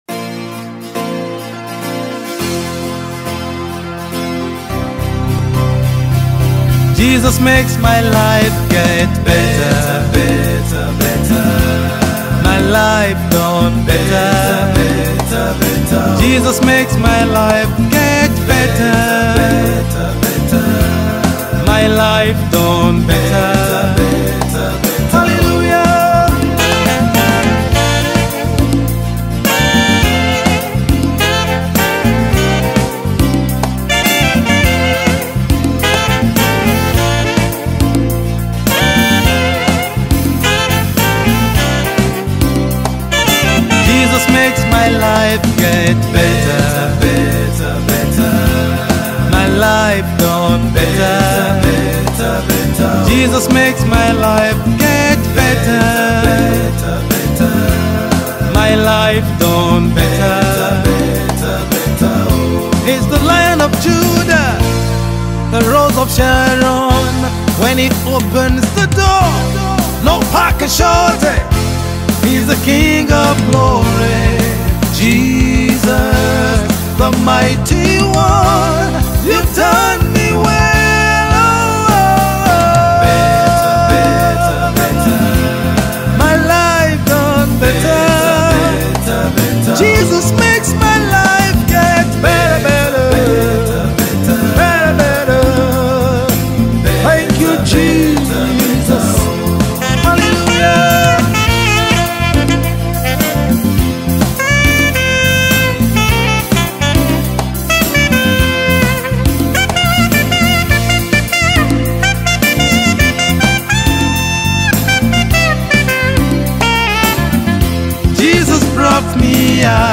January 20, 2025 Publisher 01 Gospel 0